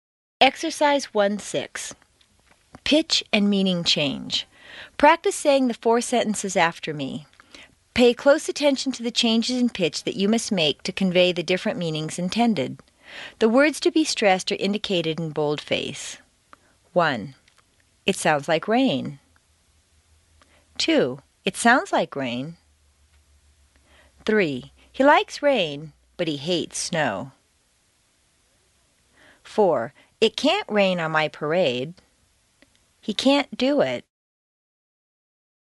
Exercise 1-6: Pitch and Meaning Change CD 1 Track 13